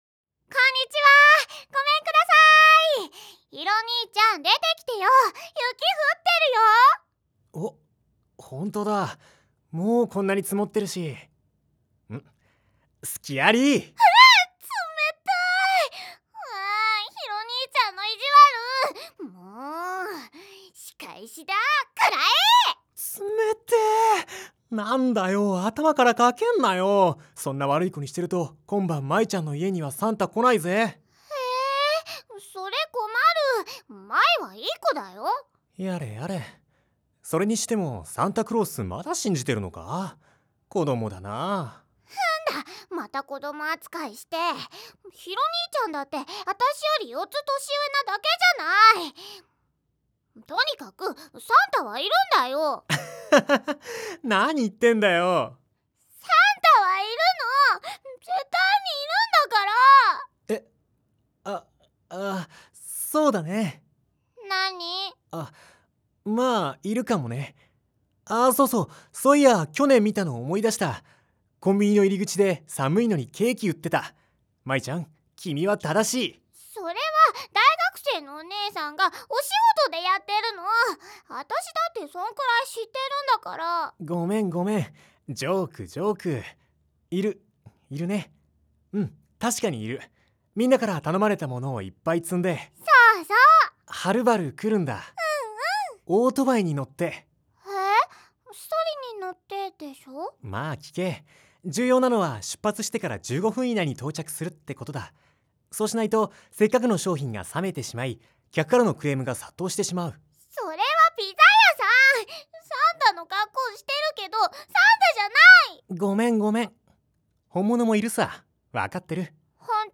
4. 声優によるオリジナル原稿の読み上げ（台詞を連結してストーリーにしたもの）
男性：VM01
女性：VF01
8.00 ［モーラ/秒］
絨毯敷，カーテン有．